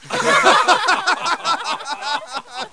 1 channel
grplaff2.mp3